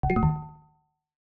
User Interface, Alert, Failure, Access Denied 03 SND57522 S02.wav